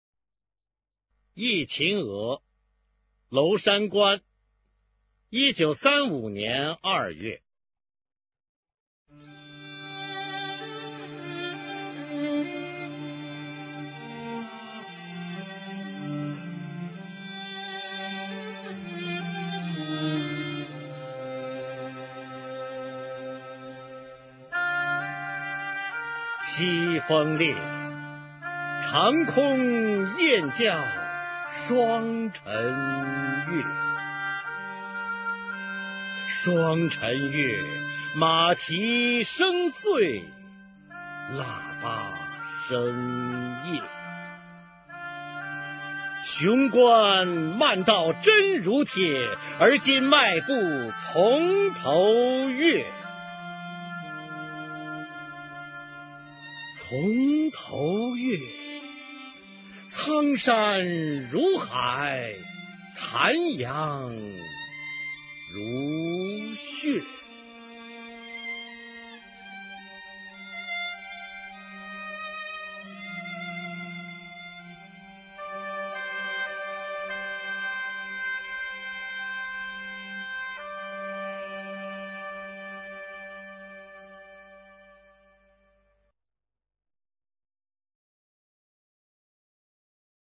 [毛泽东诗词朗诵]毛泽东-忆秦娥·娄山关（男） 配乐朗诵